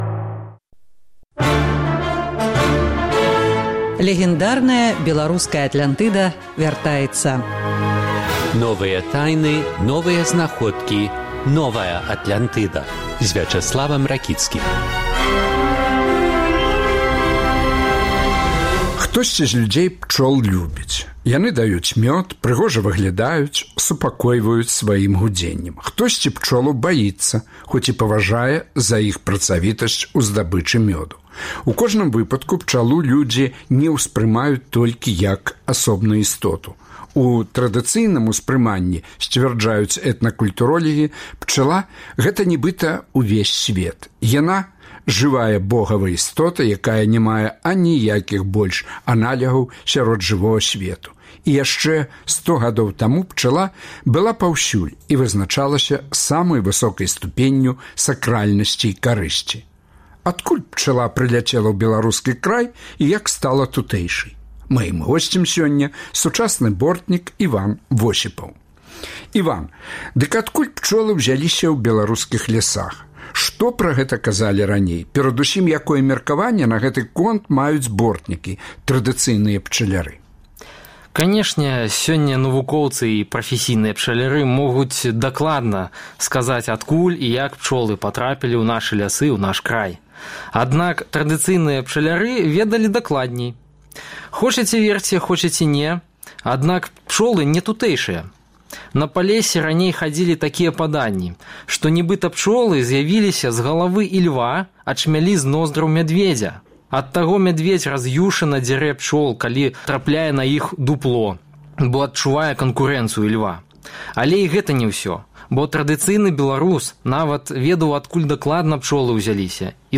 Адкуль пчала прыляцела ў беларускі край і як стала тутэйшай? Чаму яе беларусы лічаць Богавай істотай? Гутарка з сучасным бортнікам